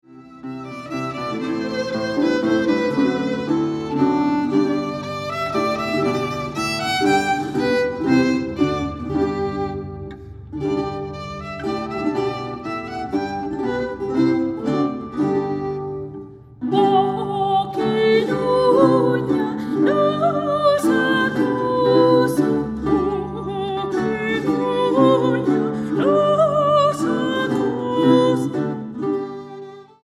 Aria
Dúo